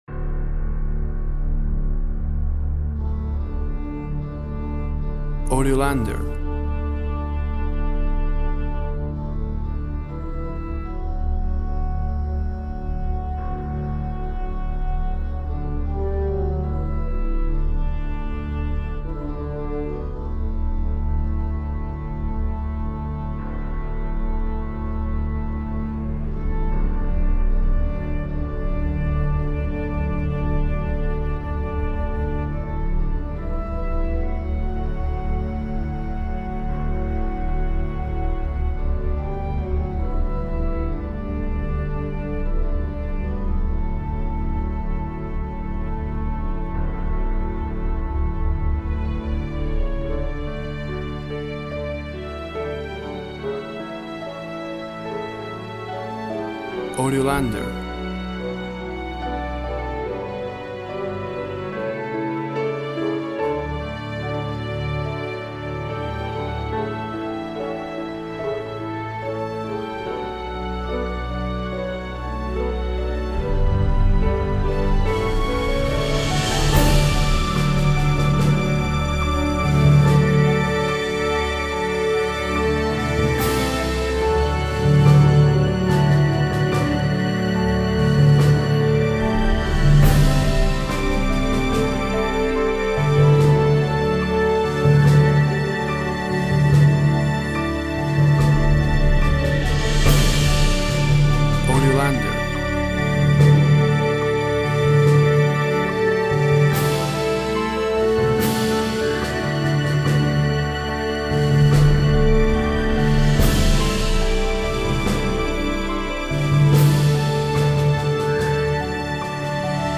A worshipful composition for full orchestra.